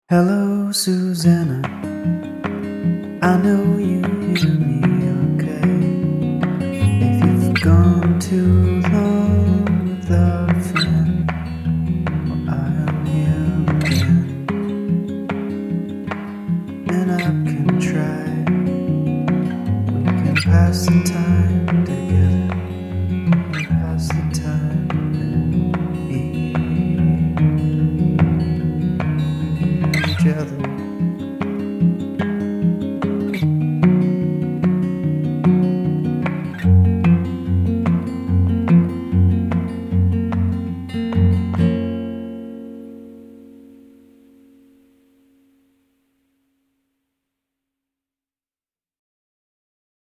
Folk
World music